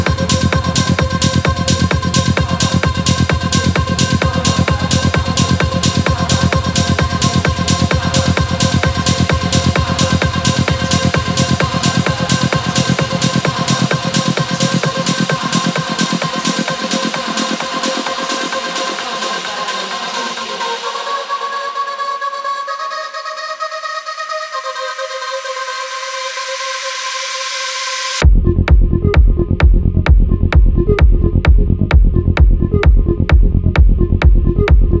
Жанр: Техно